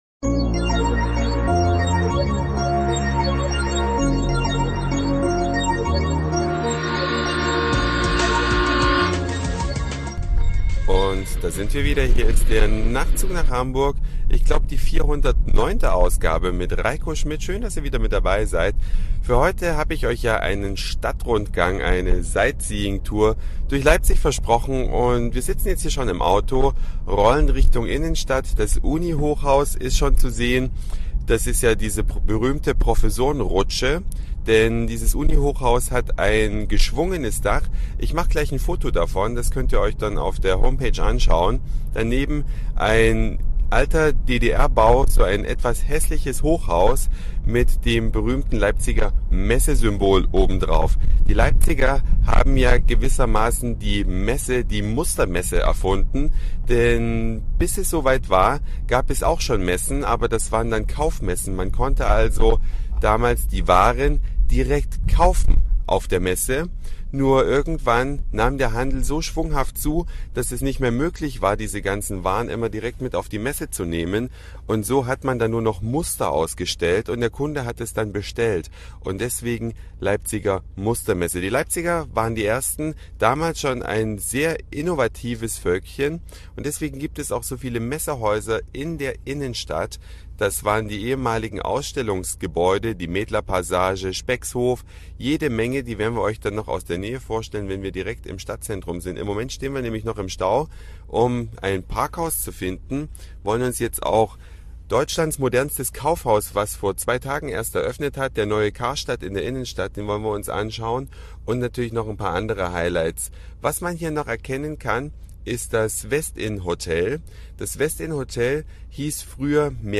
Ein akustischer Stadtrundgang durch die Messestadt Leipzig.